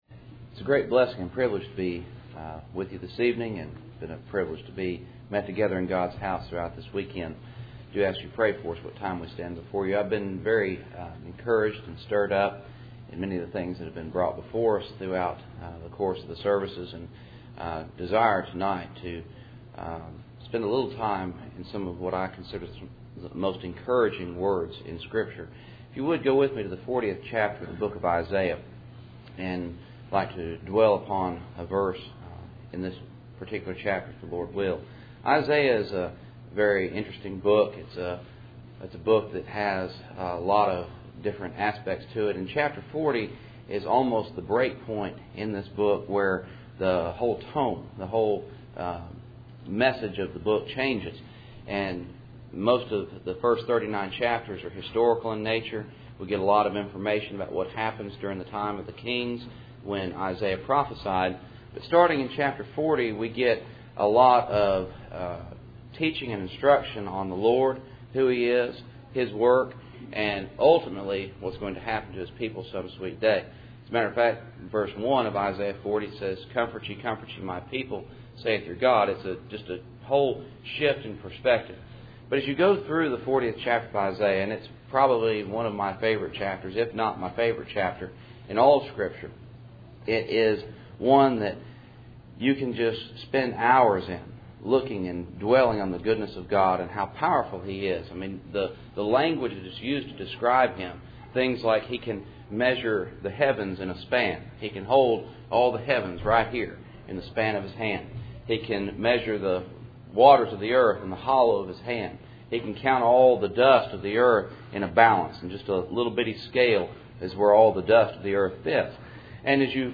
Passage: Isaiah 40:29-31 Service Type: Cool Springs PBC Sunday Evening %todo_render% « Manner II Peter 3:13-14